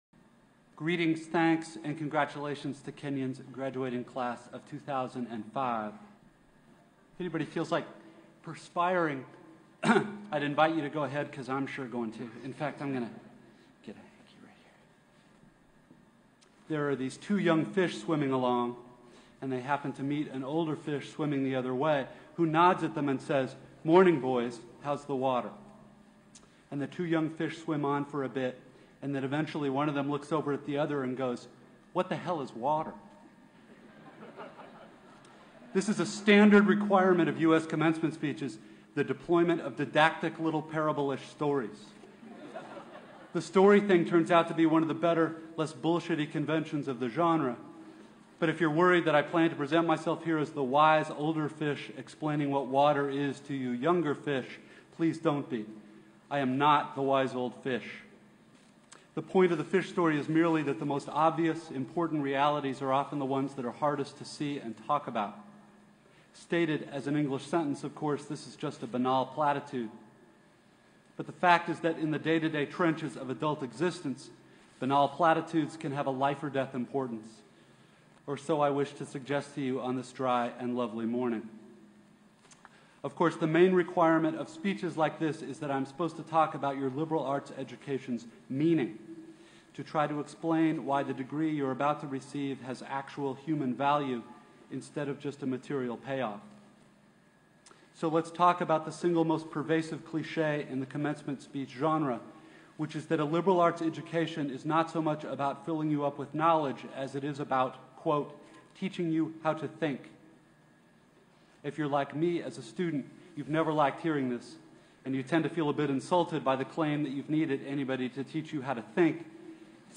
Deep Relaxation Soundscape for Focus and Recovery